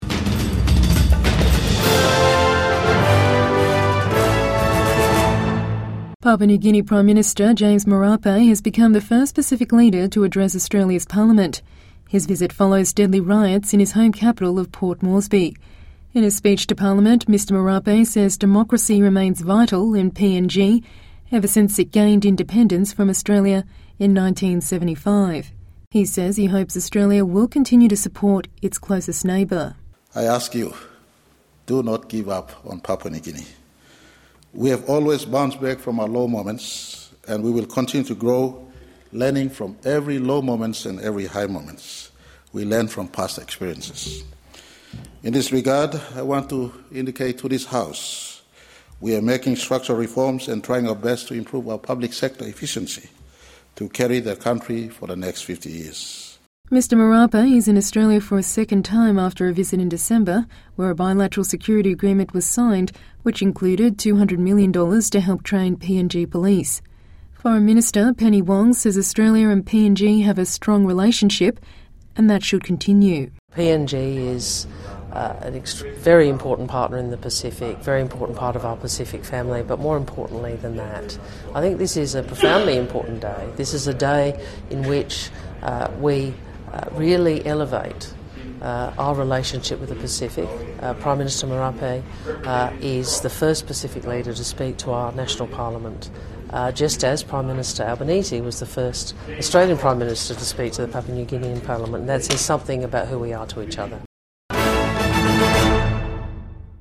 Papua New Guinea's Prime Minister addresses Australia's parliament